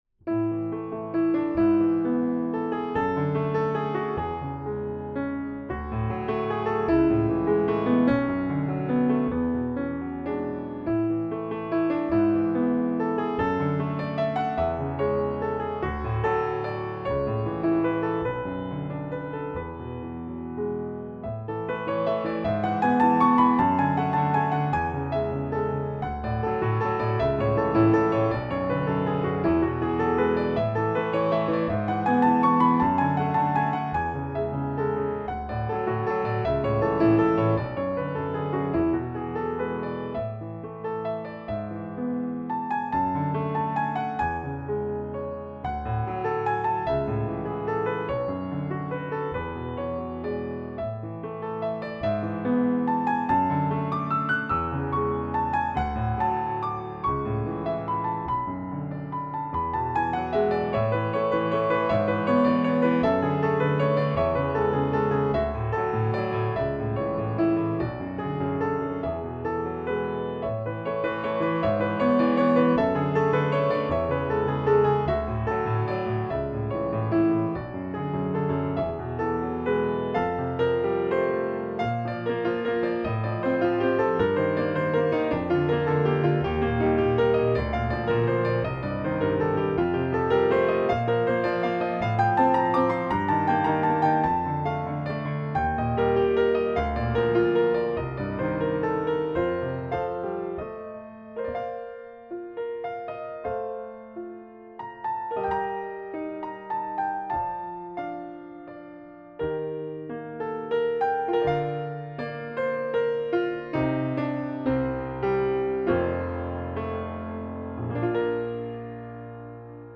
Tunes are recorded in our studio.